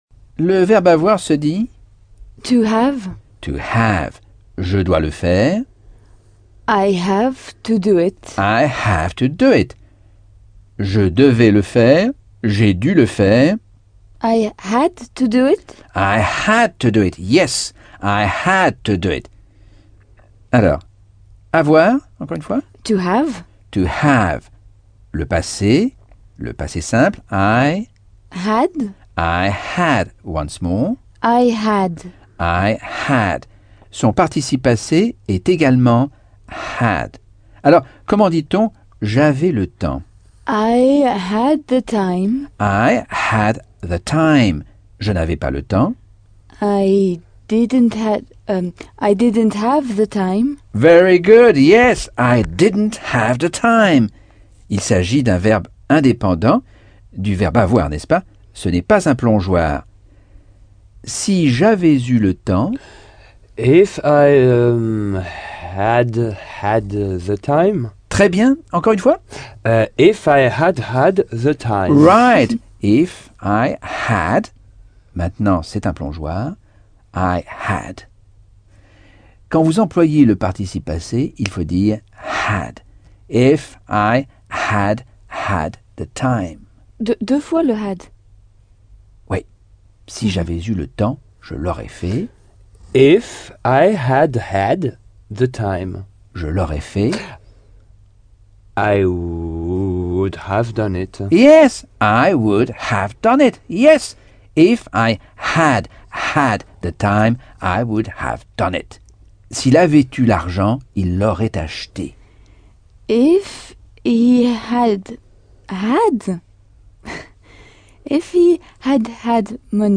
Leçon 3 - Cours audio Anglais par Michel Thomas - Chapitre 10